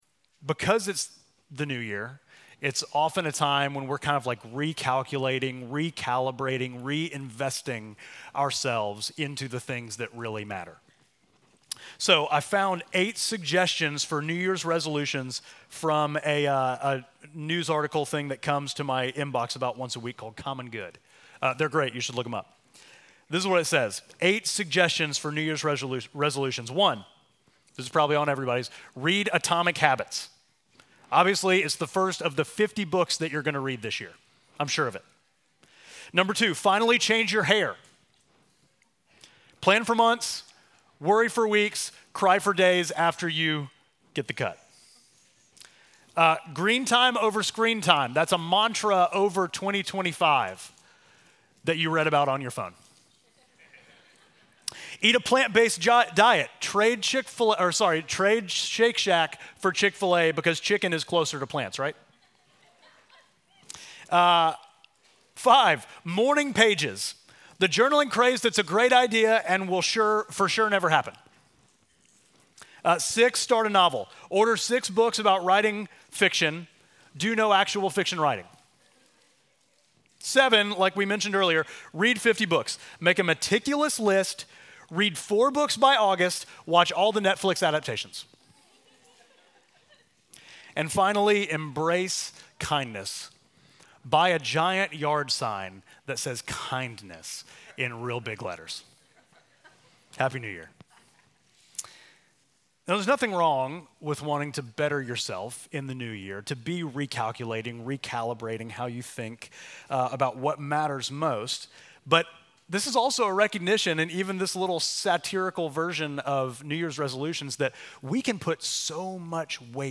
Midtown Fellowship Crieve Hall Sermons Calling for the Broken and Burned Out Jan 05 2025 | 00:33:09 1x 00:00 / 00:33:09 Subscribe Share